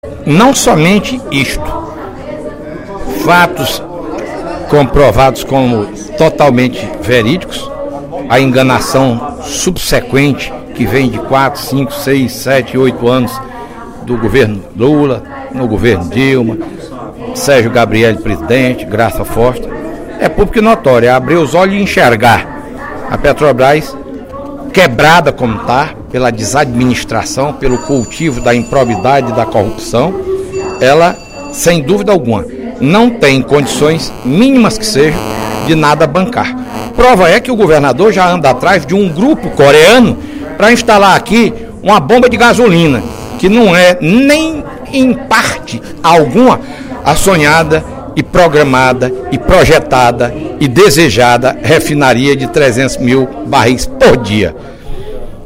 O deputado Fernando Hugo (PSDB) lembrou, durante o primeiro expediente desta sexta-feira (14/06), seu pronunciamento de terça-feira passada, quando lamentou os problemas para a instalação de refinaria no Ceará.